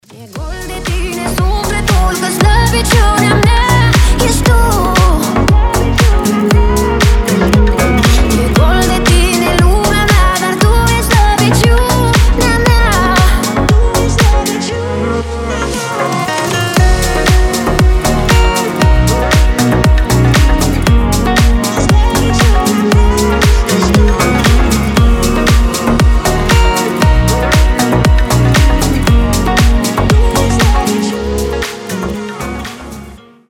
• Качество: 320, Stereo
гитара
deep house
красивая мелодия
красивый женский голос
Восхитительный румынский deep house